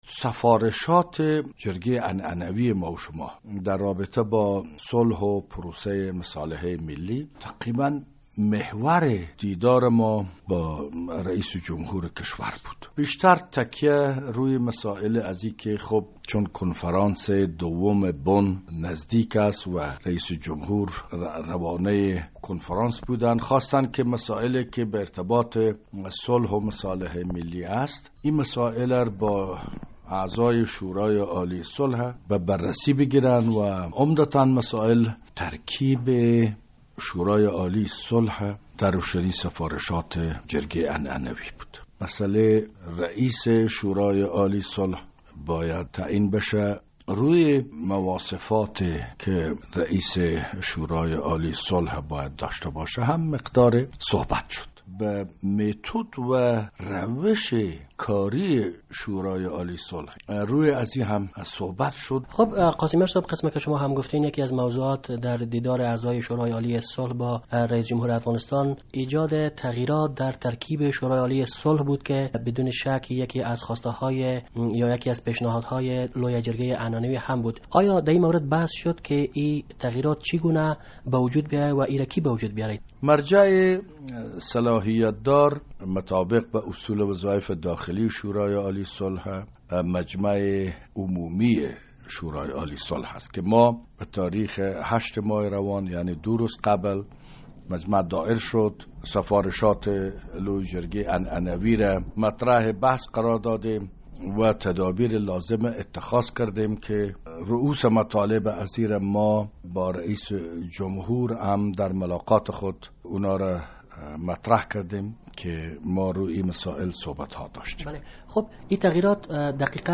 مصاحبه در مورد ملاقات اعضای شورای عالی صلح با حامد کرزی